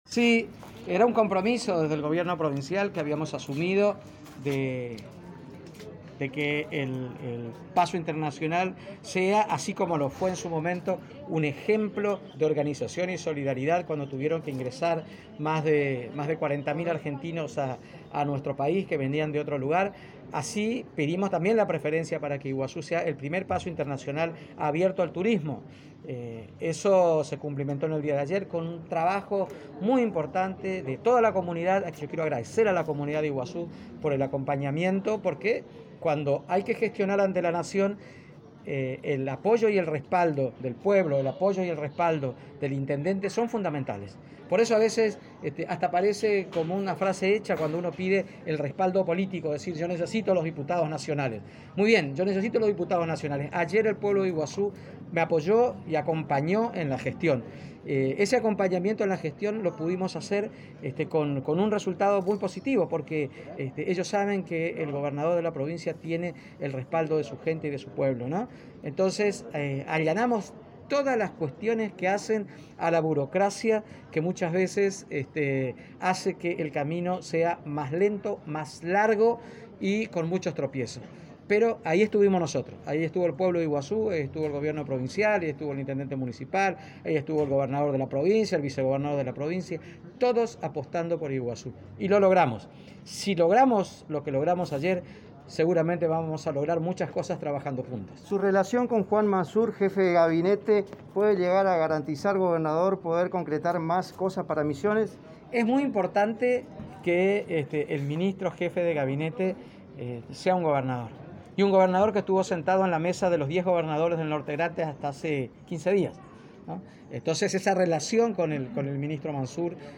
Así lo expresó el gobernador de Misiones Oscar Herrera Ahuad en el marco de la entrega 21 viviendas del Iprodha en el barrio Las Leñas de Puerto Iguazú esta tarde.
Audio: Gobernador de Misiones Oscar Herrera Ahuad